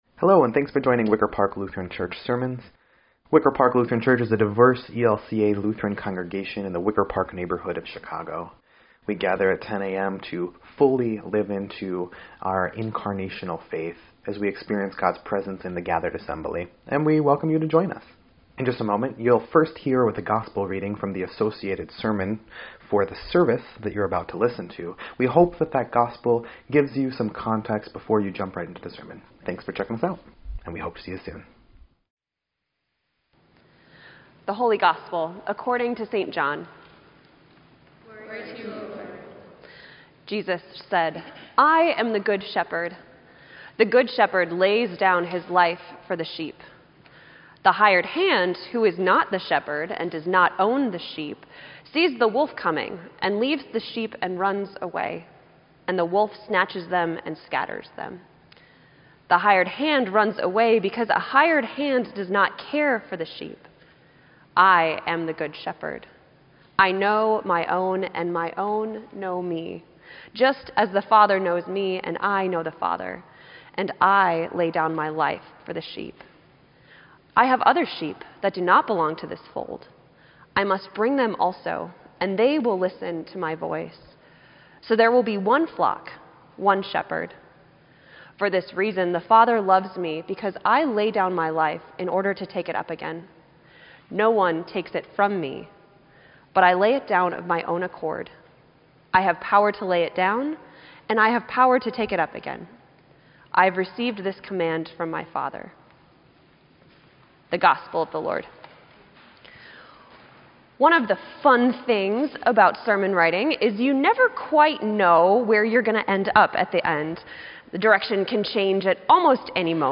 Sermon_4_21_18_EDIT.mp3